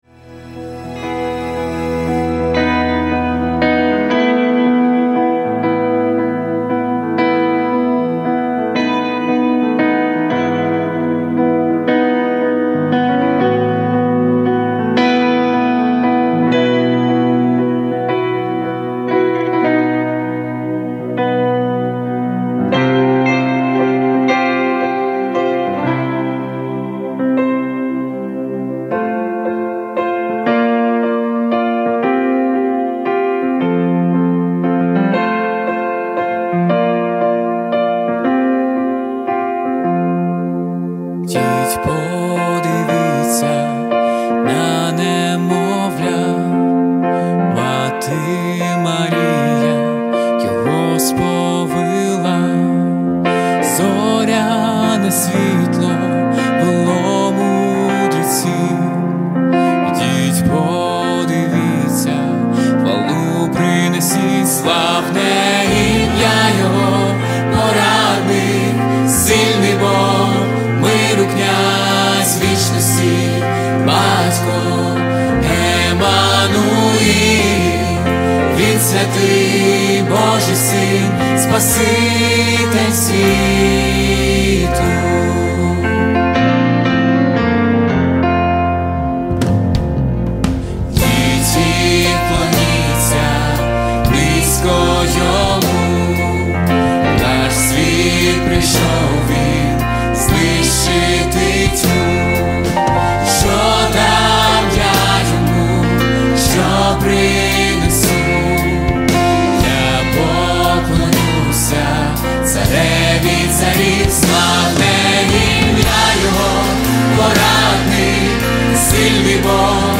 67 просмотров 74 прослушивания 3 скачивания BPM: 110